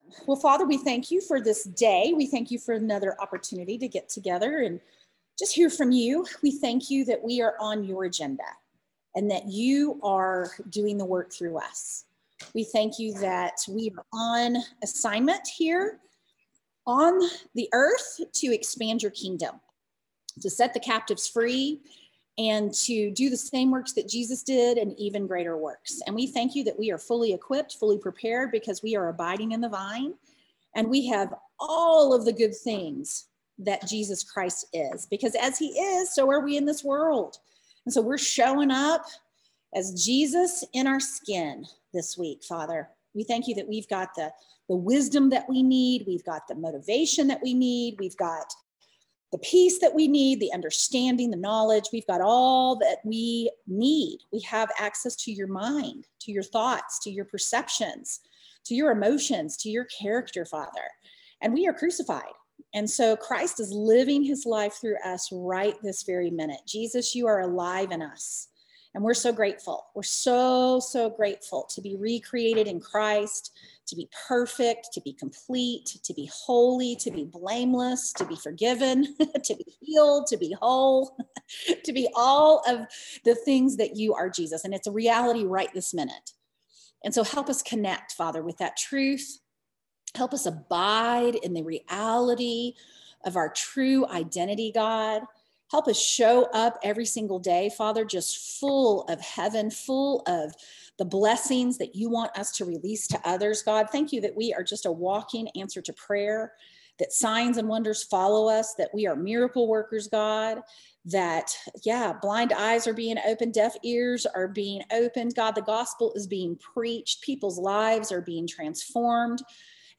59-Rise-and-Shine-Prayer.mp3